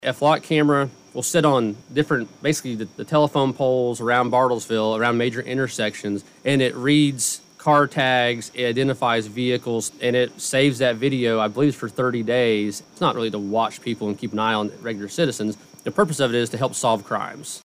District Attorney Will Drake recently joined KWON's COMMUNITY CONNECTION.